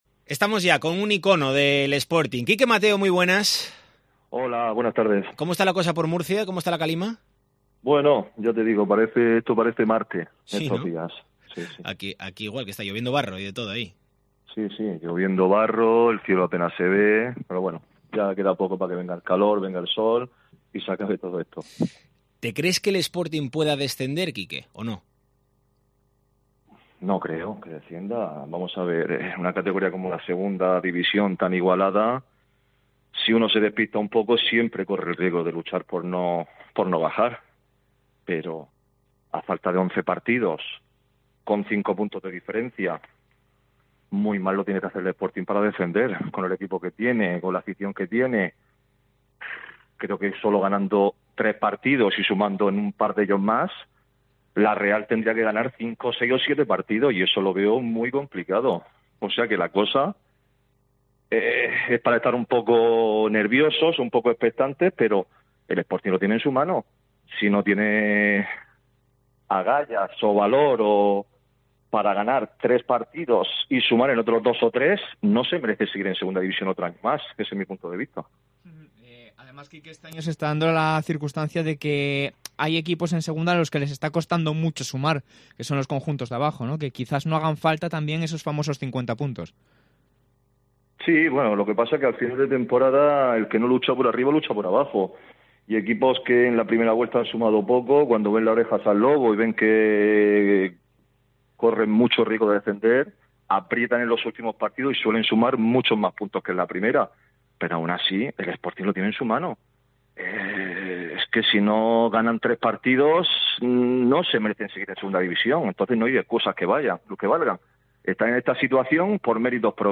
Análisis